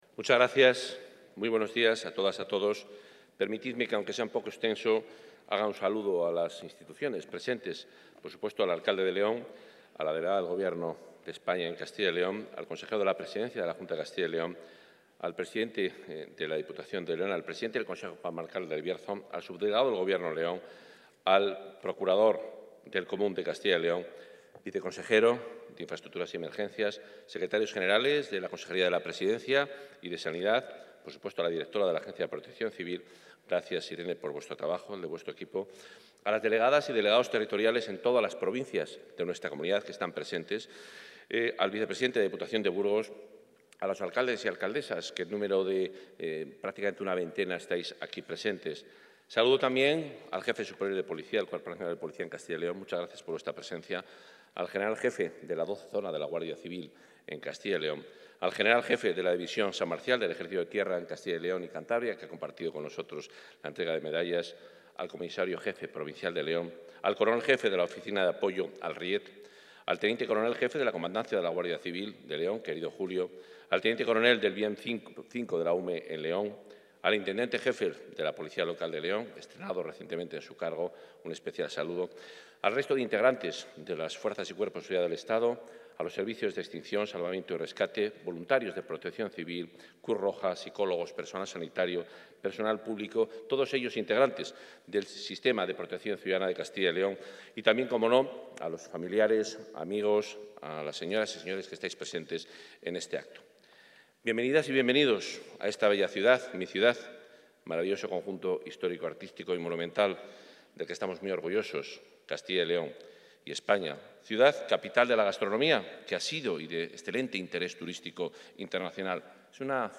Intervención del consejero de Fomento y Medio Ambiente.
El consejero de Fomento y Medio Ambiente, Juan Carlos Suárez-Quiñones, acompañado por el consejero de la Presidencia, Ángel Ibáñez, y otras autoridades, han participado hoy en el Auditorio Ciudad de León en el acto de la XI entrega de las Medallas al Mérito de Protección Ciudadana de Castilla y León y las menciones honoríficas correspondientes al año 2020.